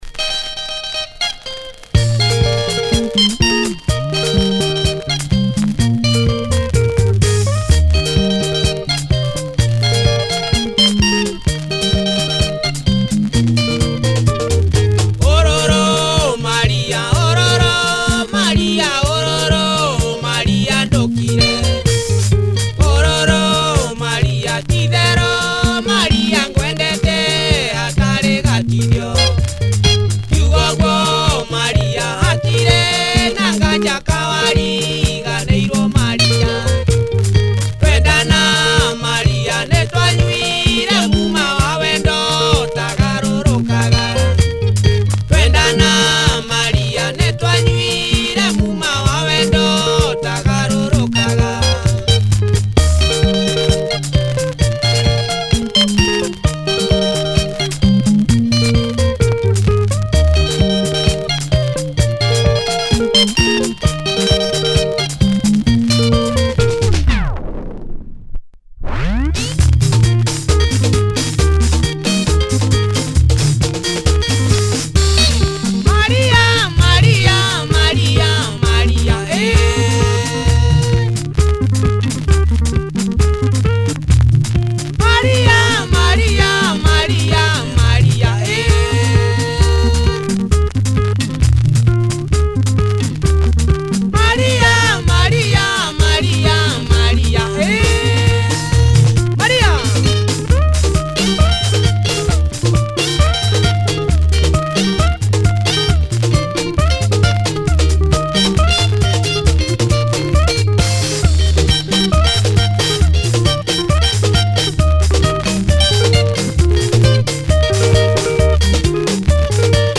benga